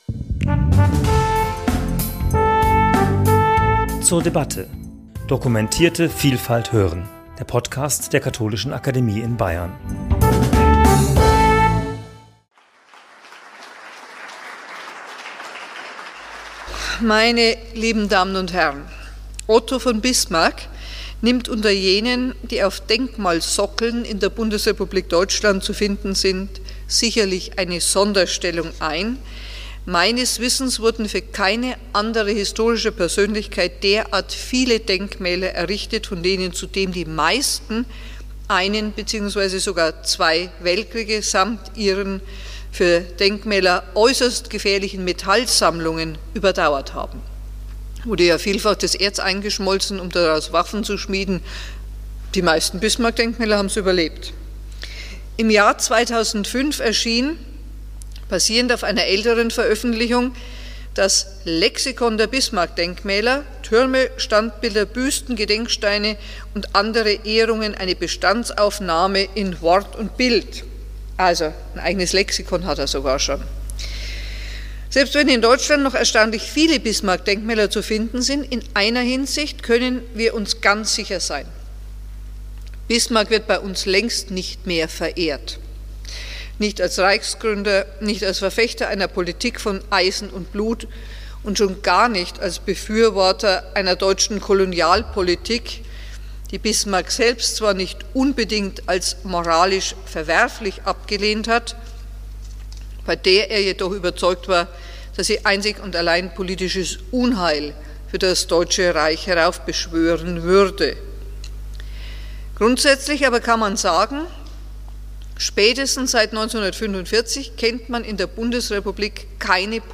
Bismarck- und Kriegerdenkmäler?" am 5.7.2021 in der Katholischen Akademie in Bayern.